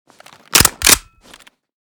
usas12_unjam.ogg